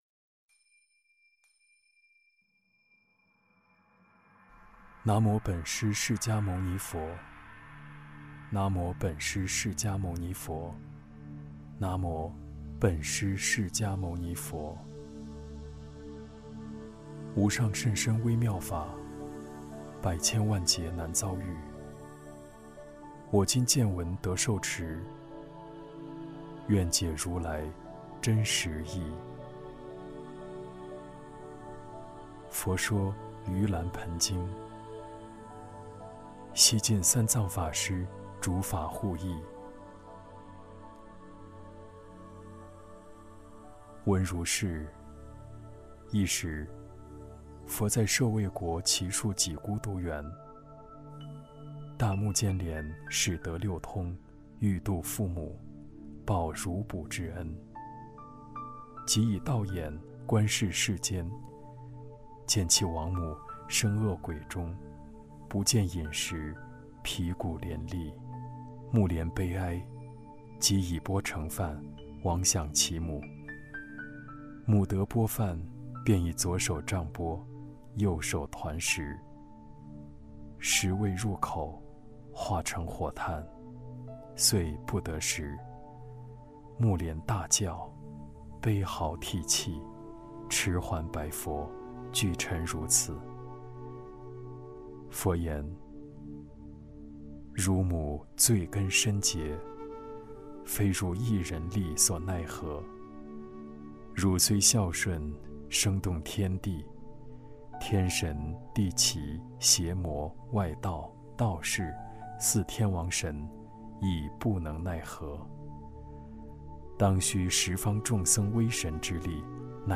梵呗 | 诵读《佛说盂兰盆经》，感受佛法中的孝亲之道